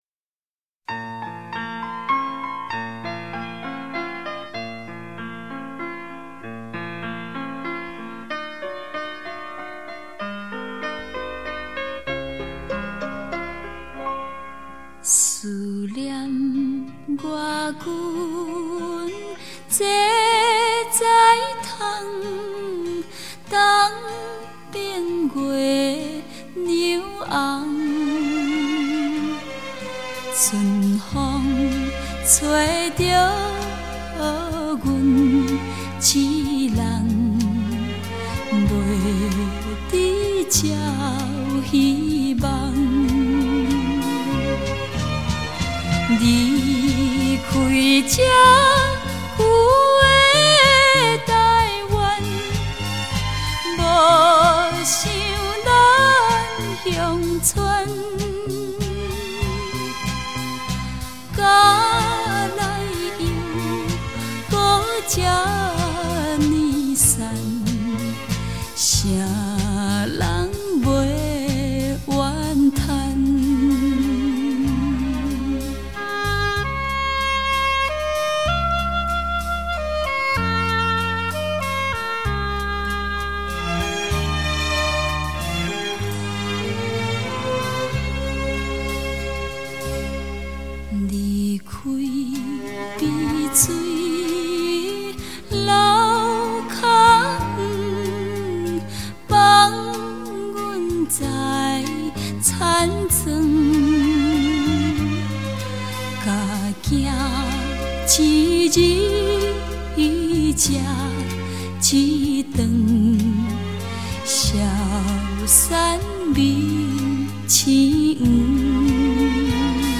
乡土的质朴 也可披上流行的风华